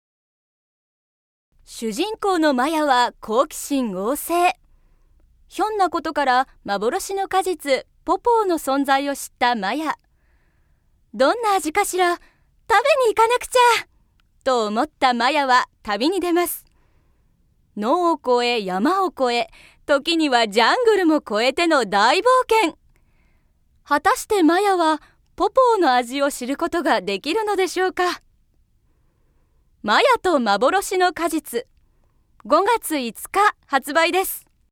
◆絵本のPRナレーション◆